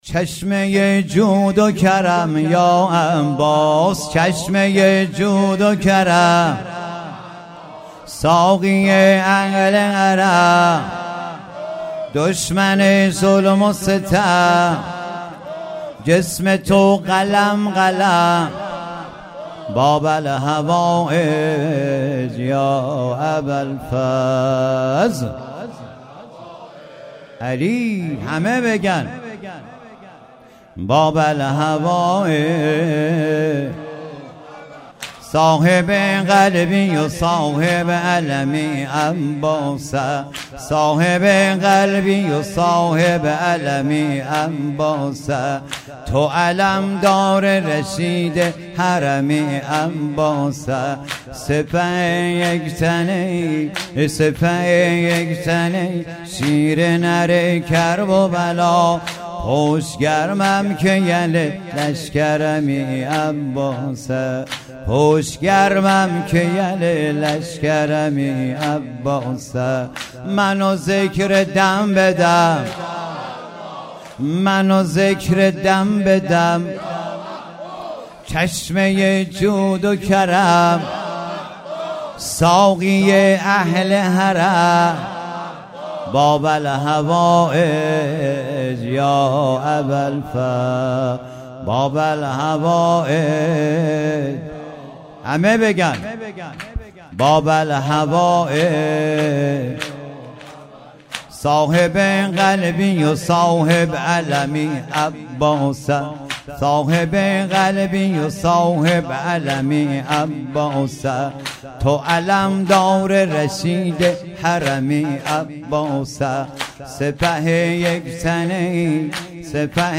شب سیزده محرم